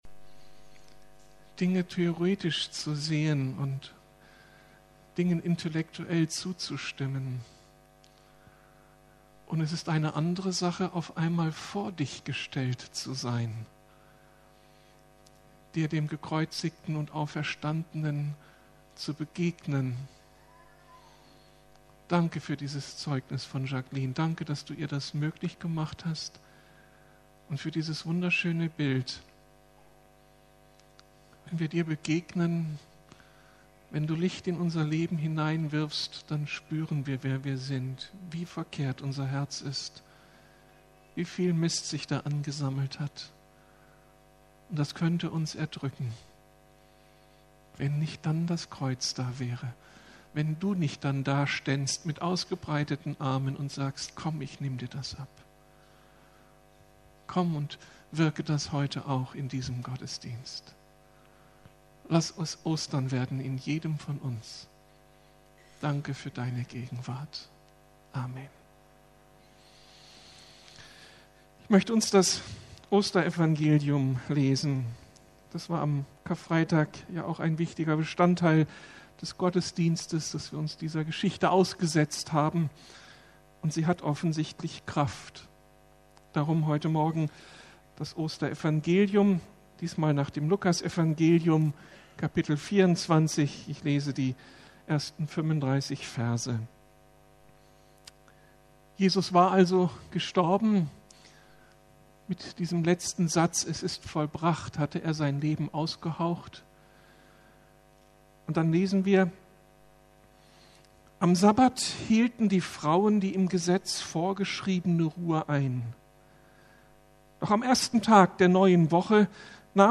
Auf dem Weg vom Zweifel zum Glauben! ~ Predigten der LUKAS GEMEINDE Podcast